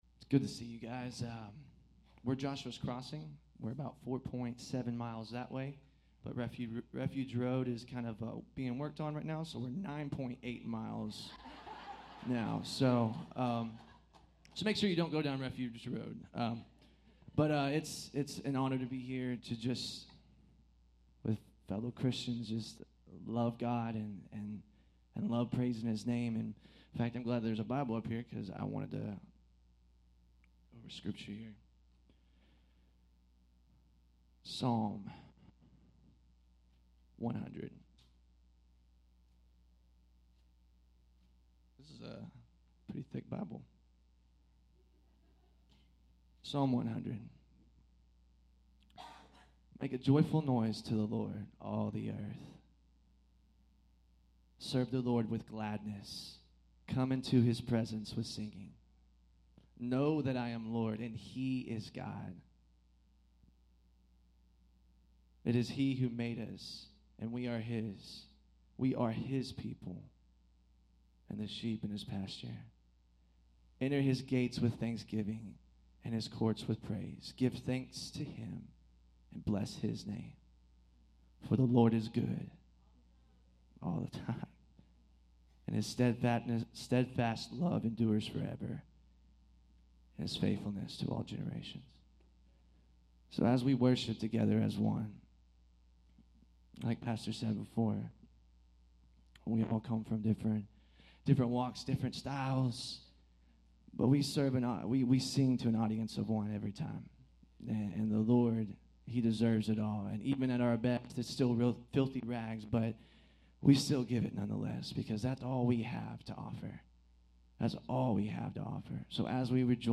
Bible Text: Colossians 3:2 | A night of worship where multiple churches came together to celebrate our Lord and Savior!